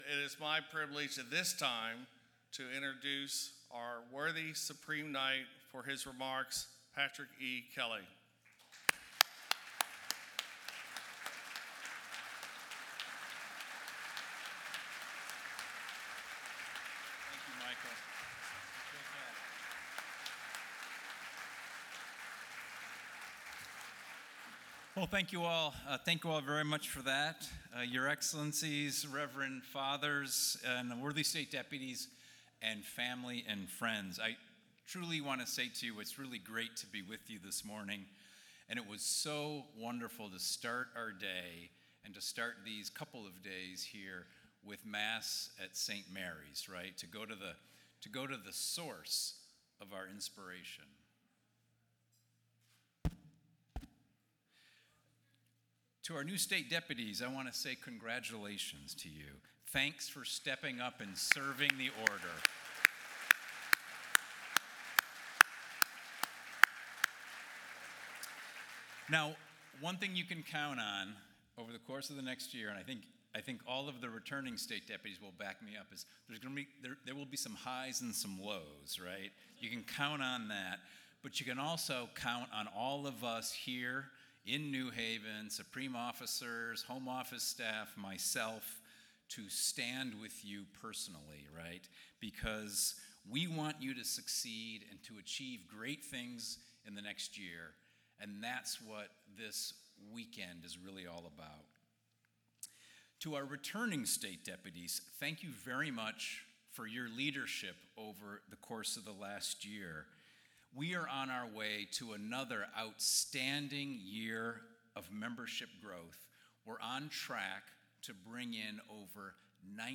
Supreme Knight's Friday Address